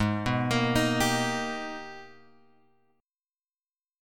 G# Minor Major 9th